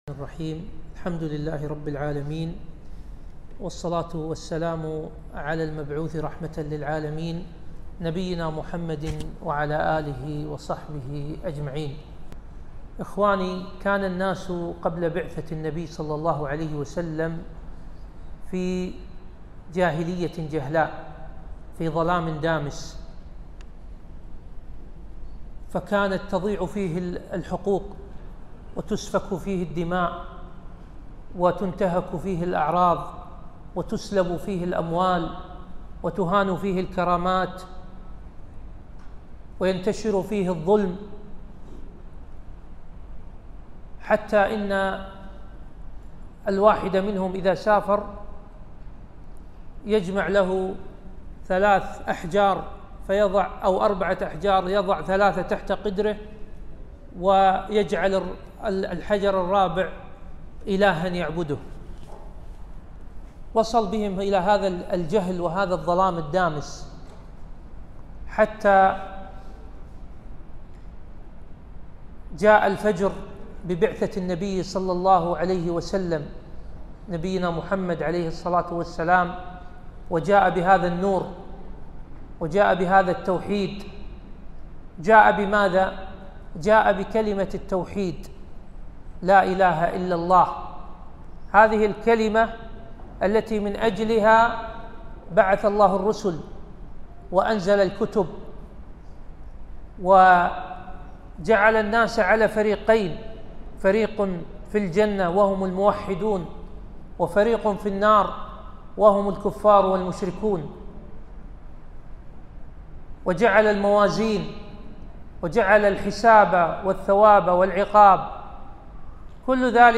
محاضرة - معنى لا إله إلا الله